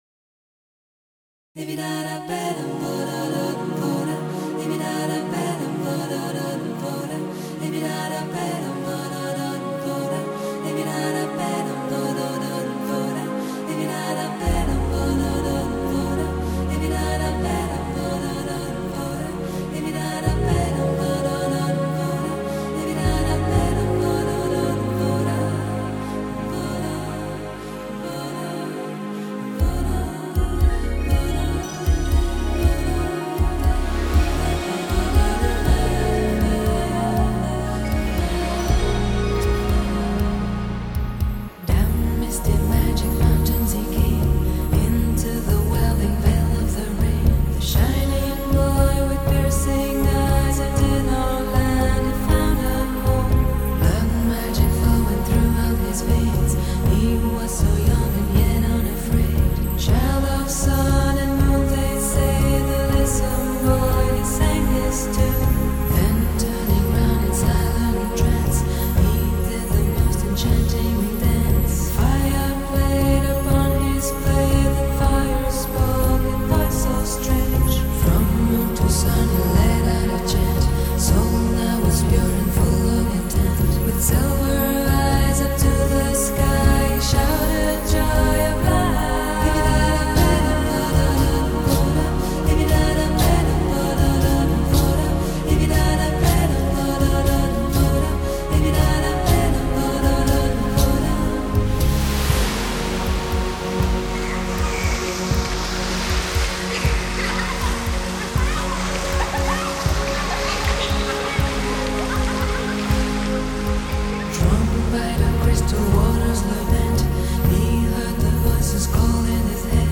有新佛林明高、浪漫钢琴、清新幽雅，南美环境音乐、排笛、敲击及女声等